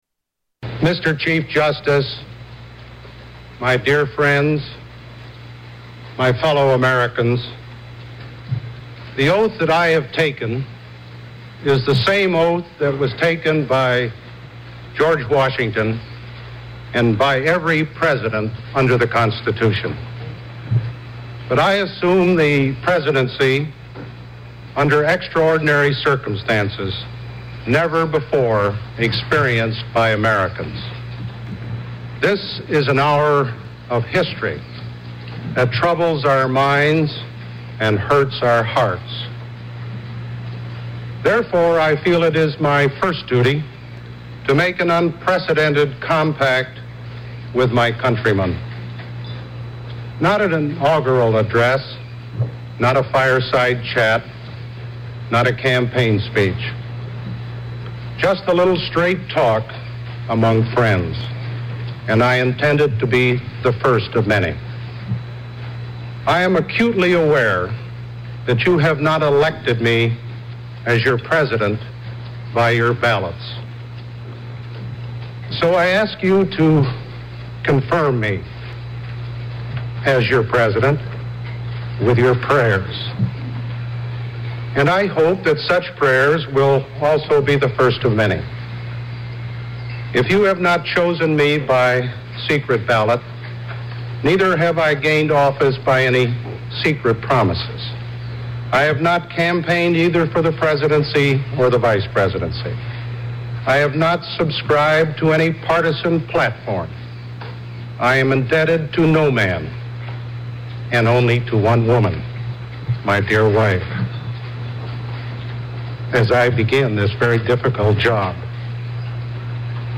Tags: Famous Inaguration clips Inaguration Inaguration speech President Obama